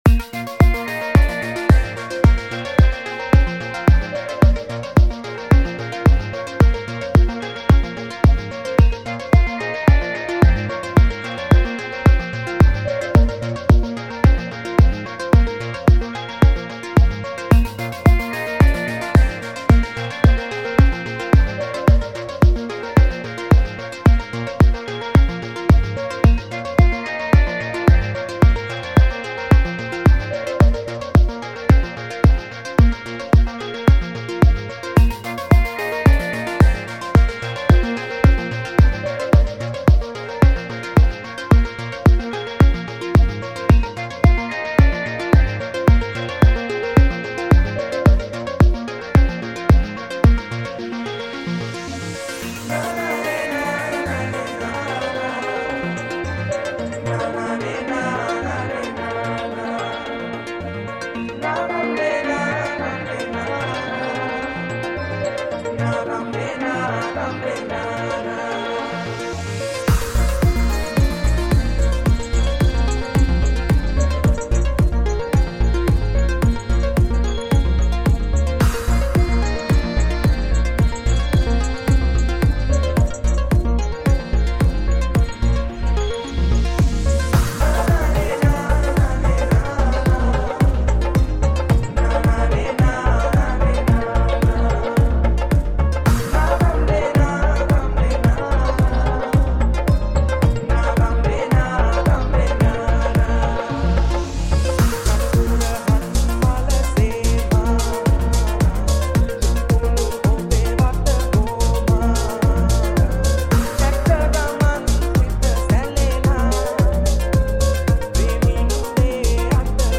High quality Sri Lankan remix MP3 (7.1).